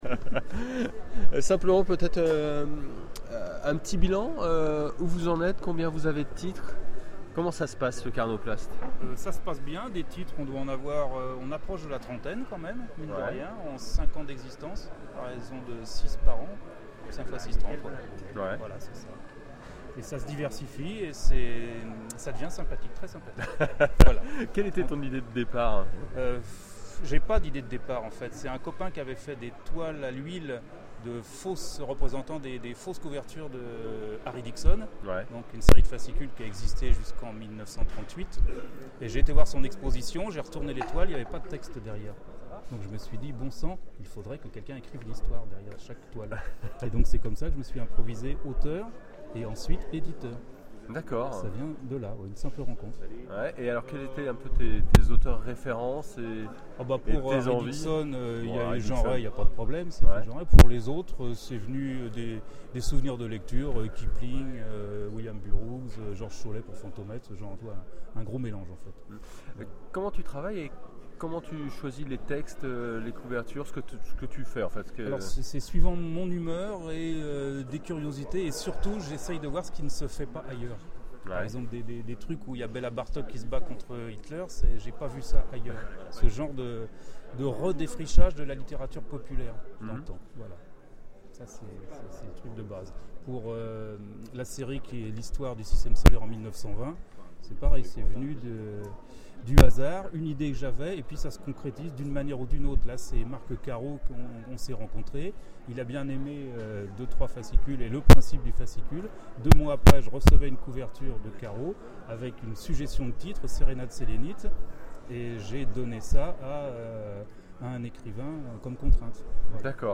Interview Le Carnoplaste